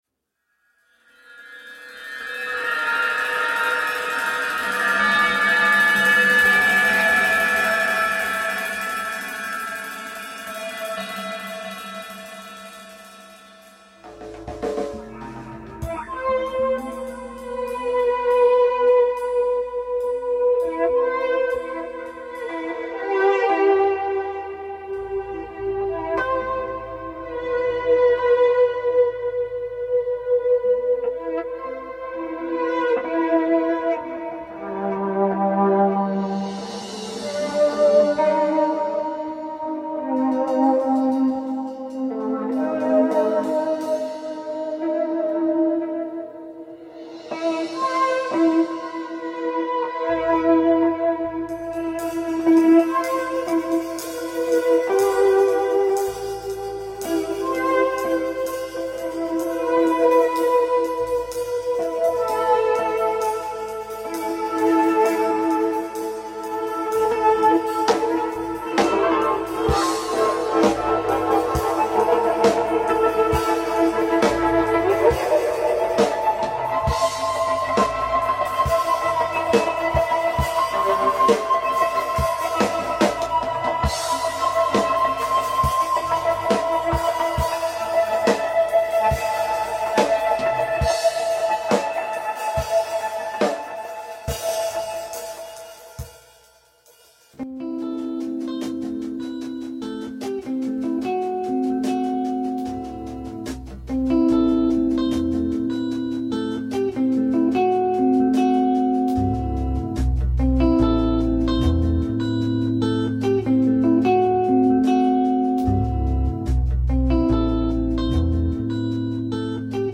Instrumental.
Experimental.
Improvised.
Mostly Pretty Sounds.
Acoustic and electric sources.
Drums, Horns, Synths, Guitars, Muchos Effectos.